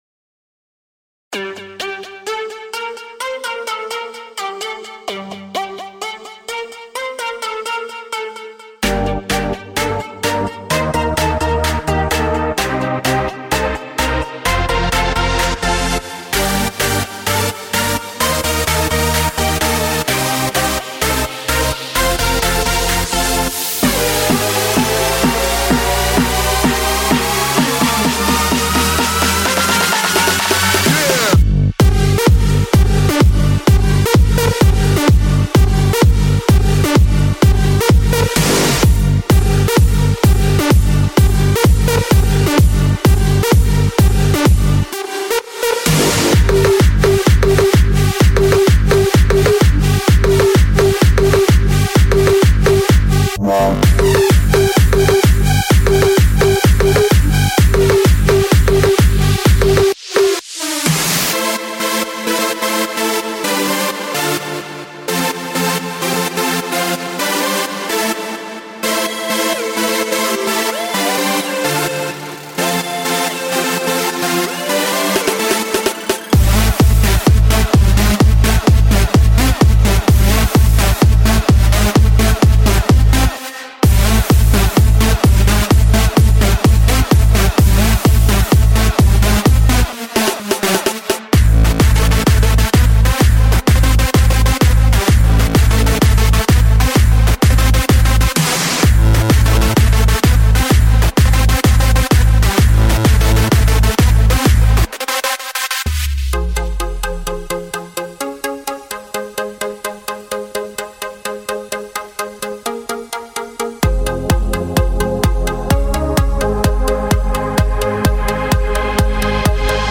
声乐循环、合成器循环、WAV 和 MIDI 格式的贝斯循环、鼓和打击乐样本、FX，当然还有许多人声、贝斯和合成器镜头，都没有任何不足之处。
Melbourne Bounce采样音效音色预制